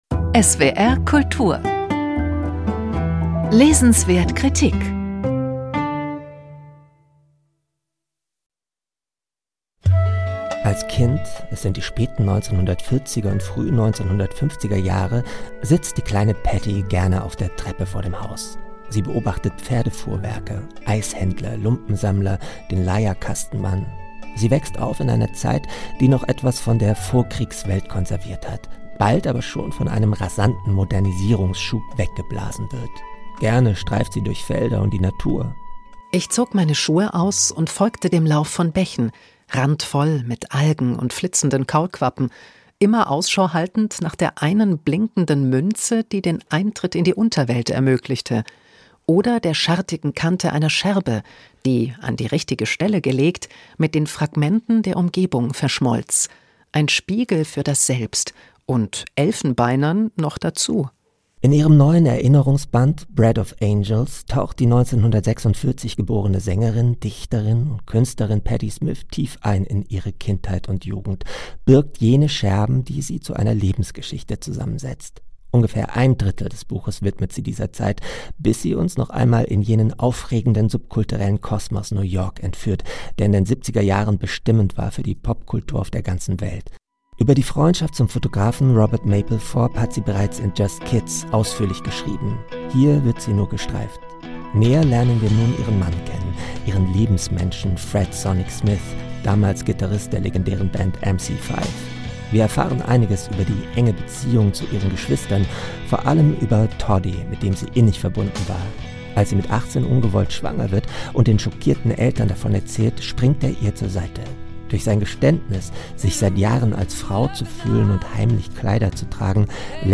Buchkritik